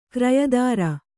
♪ krayadāra